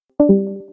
Download Discord Leave sound effect for free.